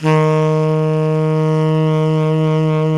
SAX TENORB09.wav